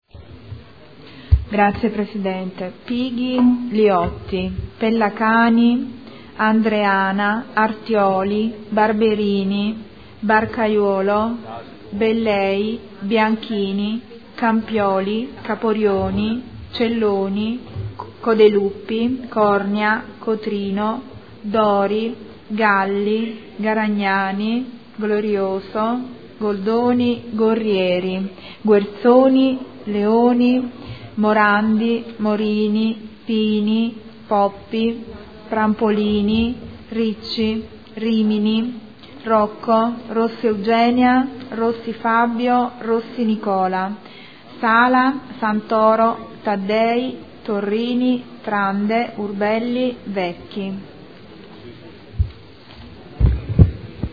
Appello